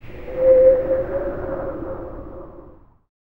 TUV NOISE 03.wav